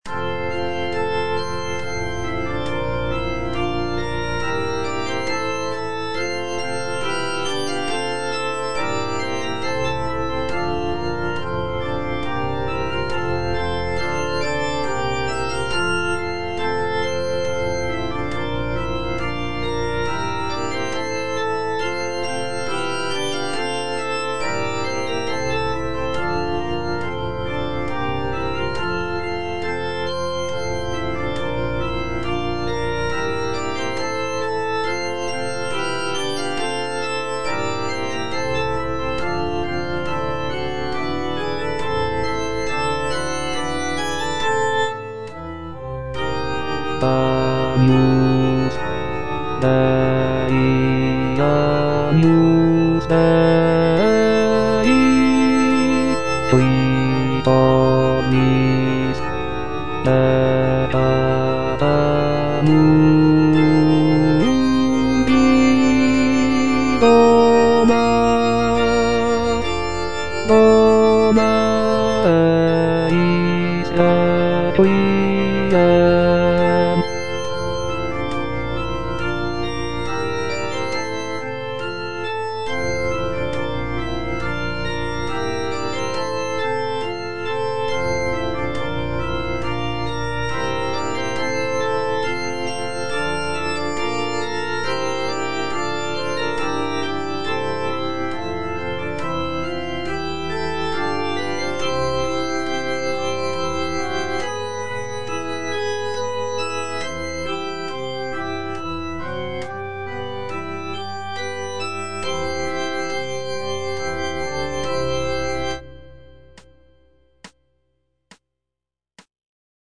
G. FAURÉ - REQUIEM OP.48 (VERSION WITH A SMALLER ORCHESTRA) Agnus Dei (bass I) (Voice with metronome) Ads stop: